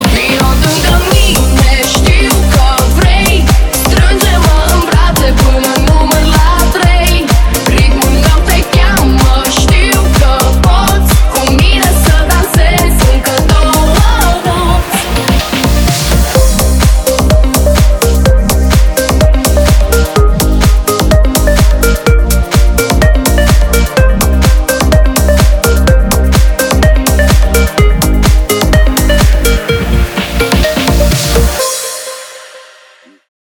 • Качество: 320, Stereo
громкие
зажигательные
EDM
Club House
энергичные
быстрые
Зажигательный ремикс песни румынской исполнительницы